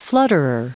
Prononciation du mot flutterer en anglais (fichier audio)
Prononciation du mot : flutterer
flutterer.wav